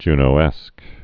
(jnō-ĕsk)